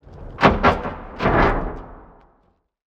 Crack.wav